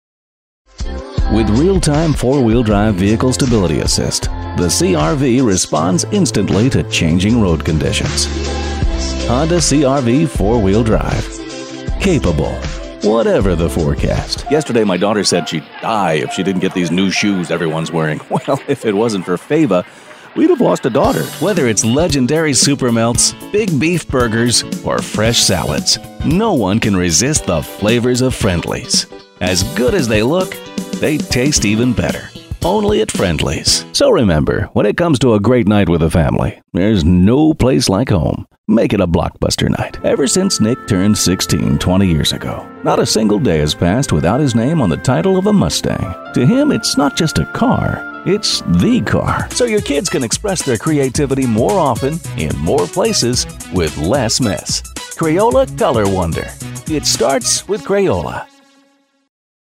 Season voice actor, producer and director as well as character voice specialist.
mid-atlantic
middle west
Sprechprobe: Werbung (Muttersprache):